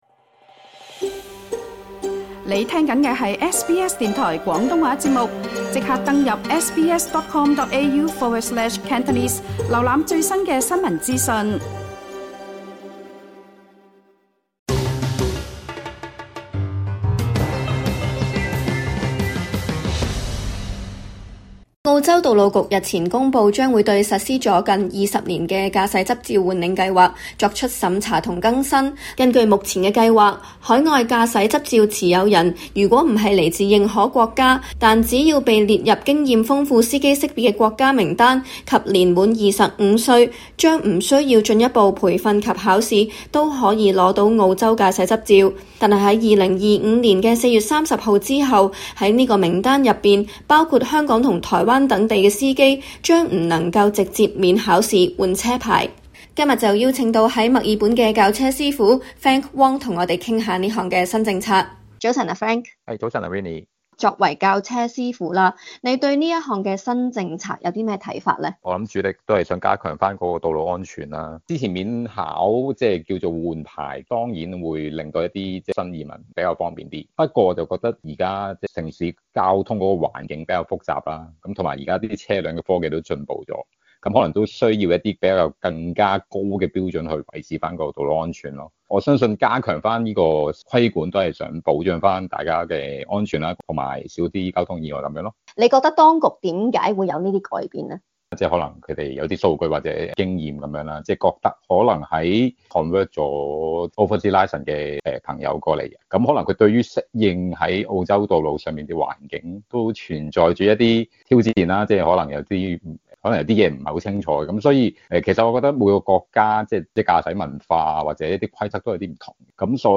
【時事專訪】